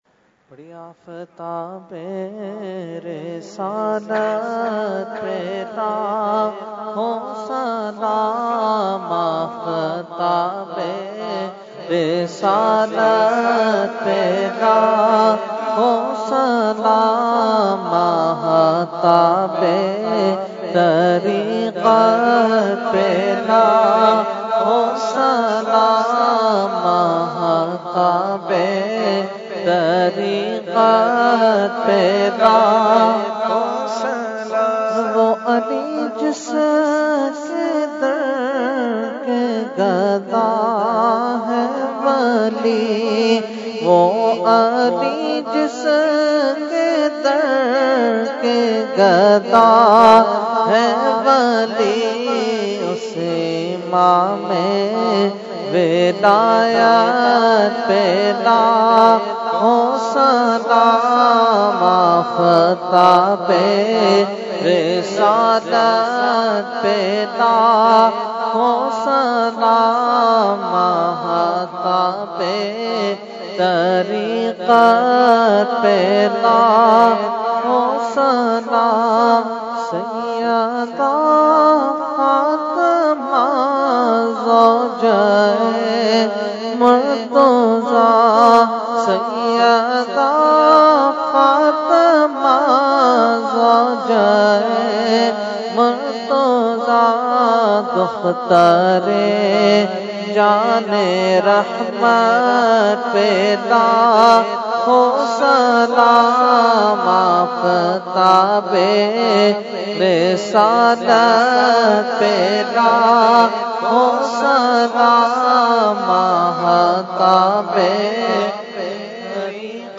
Category : Salam | Language : UrduEvent : Urs Qutbe Rabbani 2019